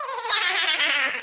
Sound effect from New Super Mario Bros.
Boo_Laugh_2.oga.mp3